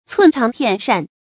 寸長片善 注音： ㄘㄨㄣˋ ㄔㄤˊ ㄆㄧㄢˋ ㄕㄢˋ 讀音讀法： 意思解釋： 寸長：一點兒長處。